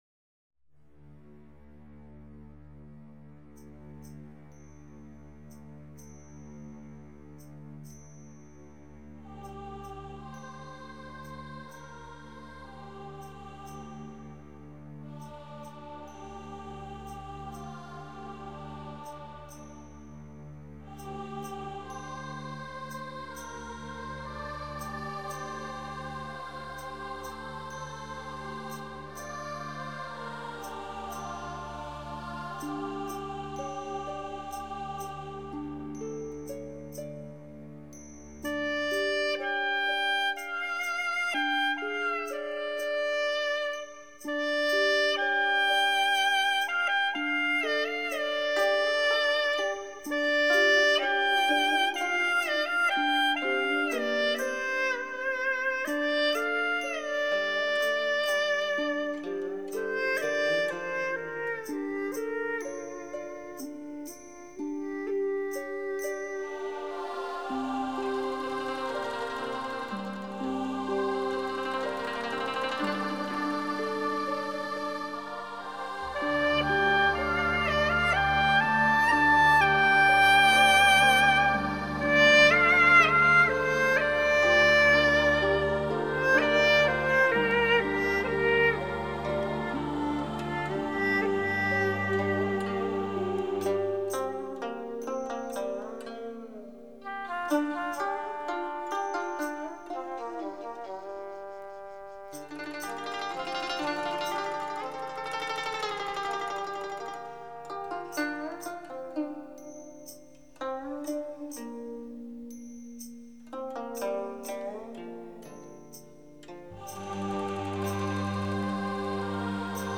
本辑是青海民歌改编的轻音乐。中国民族乐器为主奏，辅以西洋管弦乐器及电声乐器。
最新数码系统录制，堪称民乐天碟。